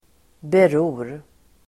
Uttal: [ber'o:r]